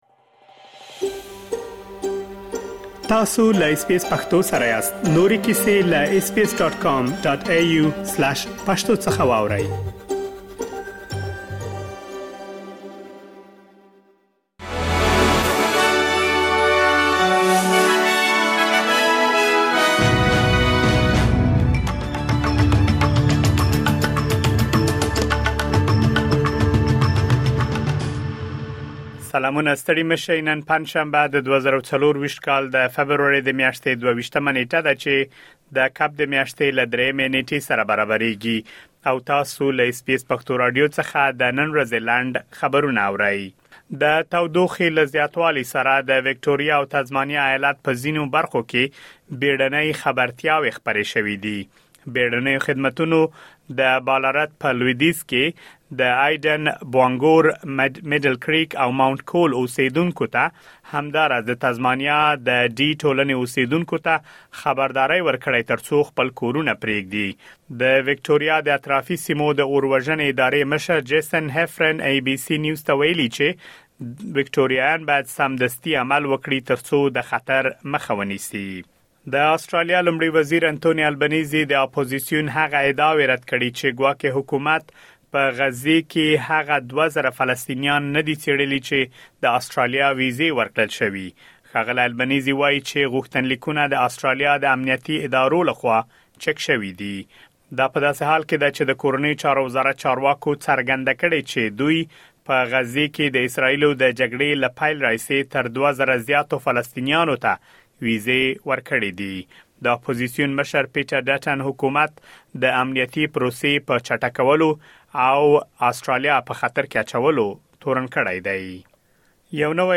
د اس بي اس پښتو راډیو د نن ورځې لنډ خبرونه دلته واورئ.